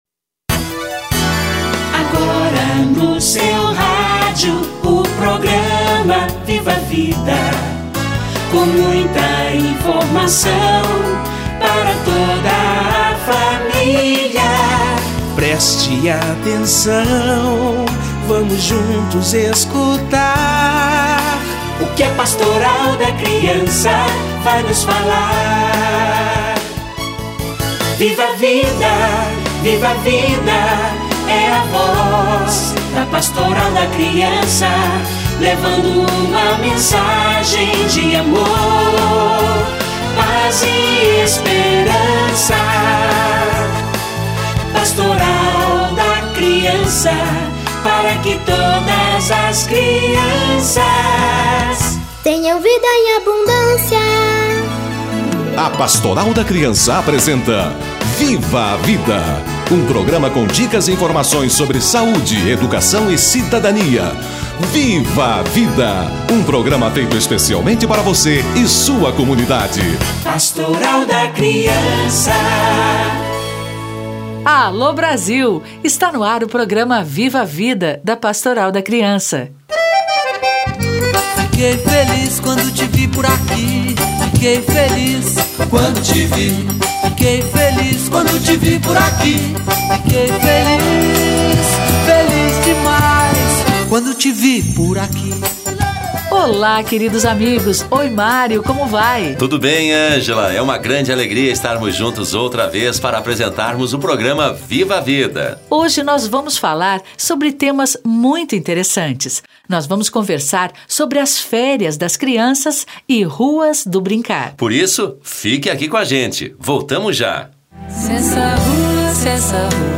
Férias e Ruas do Brincar - Entrevista